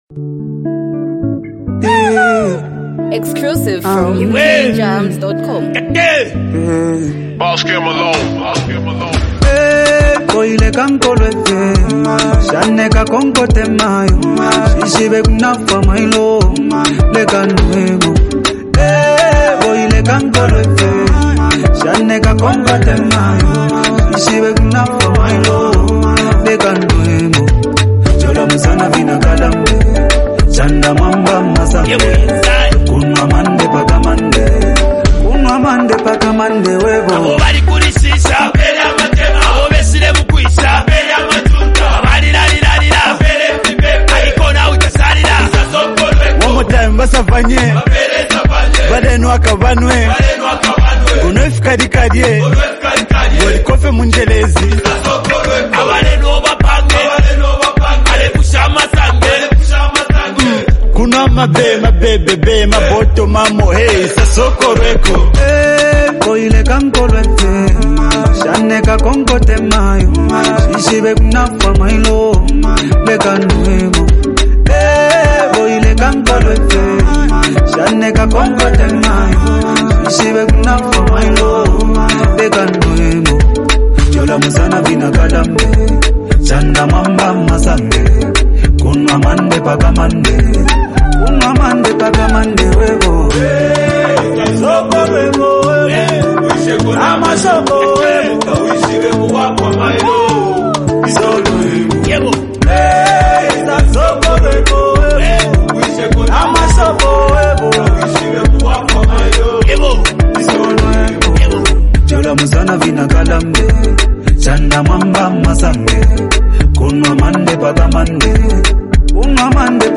a heartfelt and emotionally rich song
signature soulful vocals